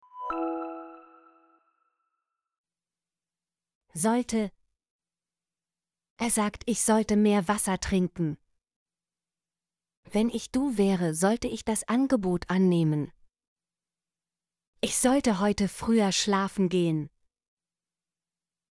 sollte - Example Sentences & Pronunciation, German Frequency List